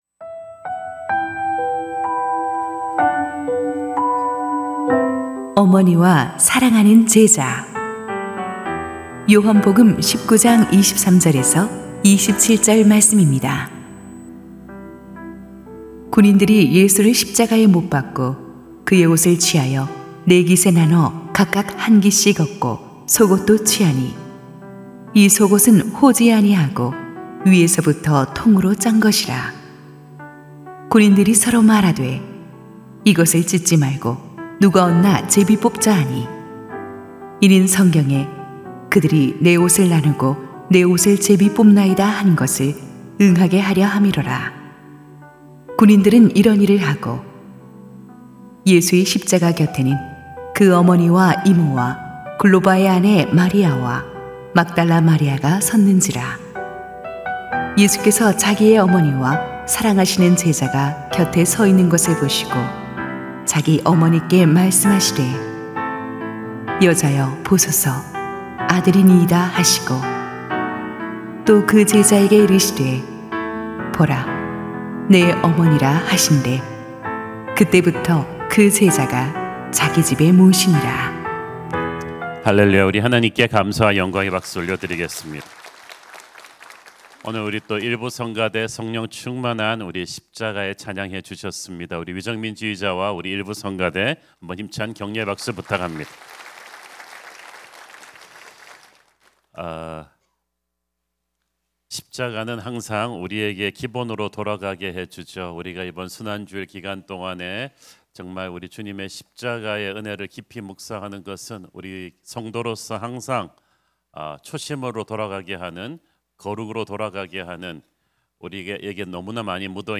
> 설교
[새벽예배]